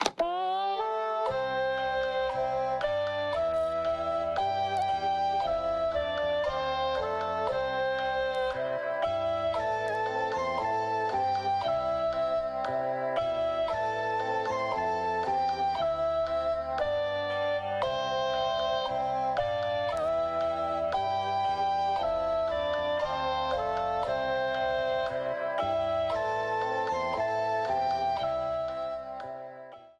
Ripped from the game
clipped to 30 seconds and applied fade-out